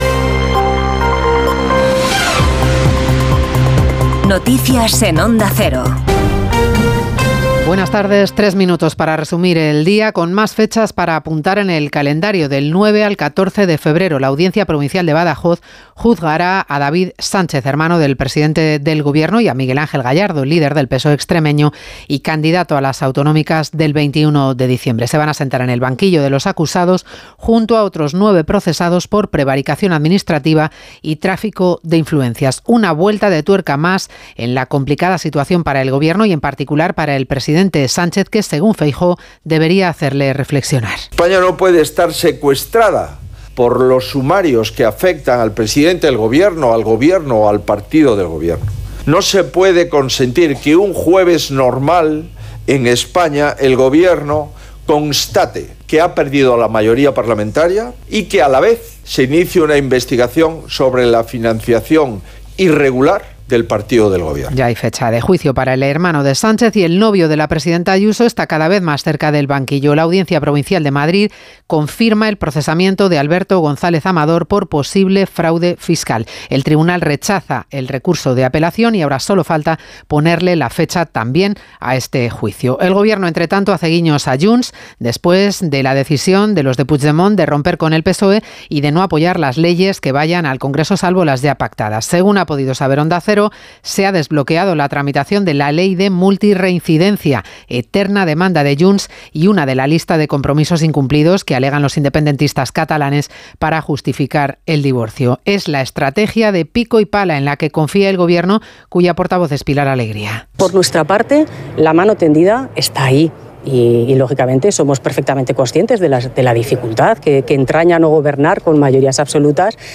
Conoce la ultima hora y toda la actualidad del dia en los boletines informativos de Onda Cero. Escucha hora a hora las noticias de hoy en Espana y el mundo y mantente al dia con la informacion deportiva.